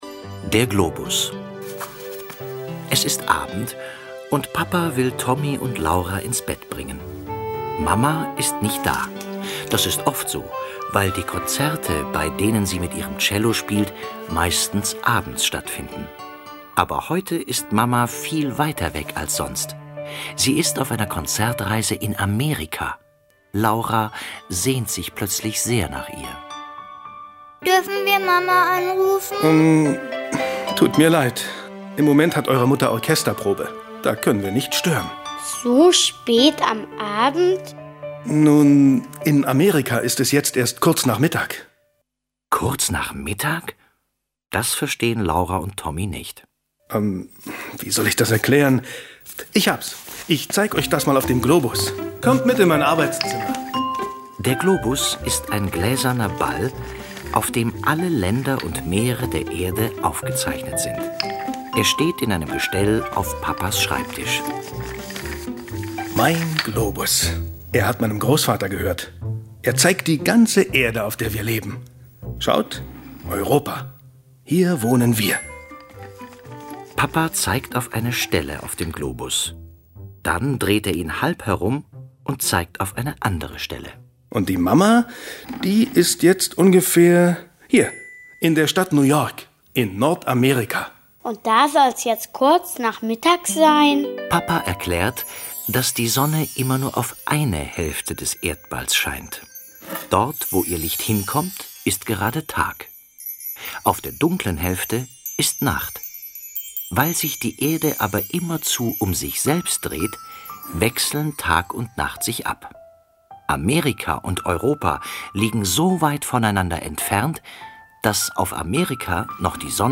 Tonspur der TV-Serie, Folge 2.